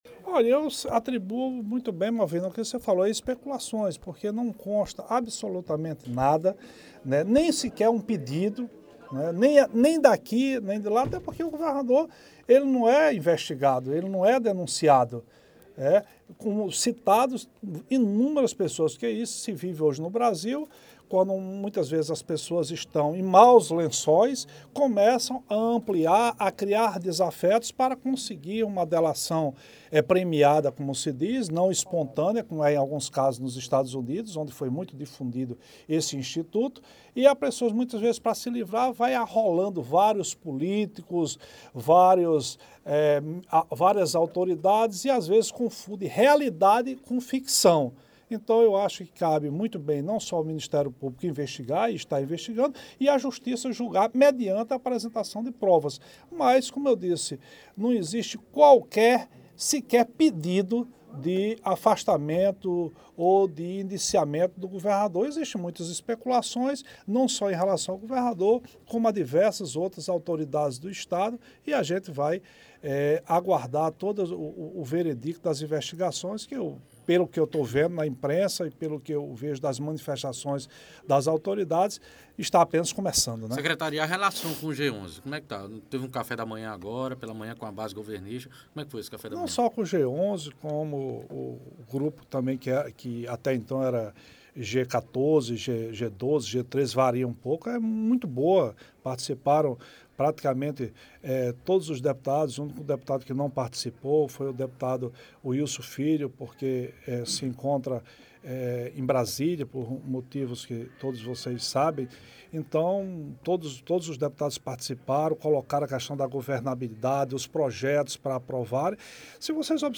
Nesta quarta-feira (5), durante abertura dos trabalhos legislativo da Assembleia Legislativa da Paraíba (ALPB), o secretário de Comunicação do Estado, Nonato Bandeira, negou qualquer possibilidade de existir uma suposta articulação dos deputados para apresentar um pedido de afastamento do governador João Azevêdo, como também um pedido vindo da Justiça.